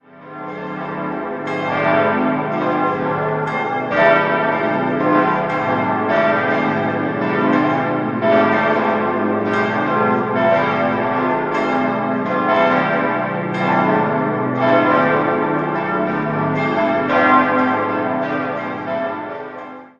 5-stimmiges Geläut: a°-c'-d'-e'(+)-g'(+)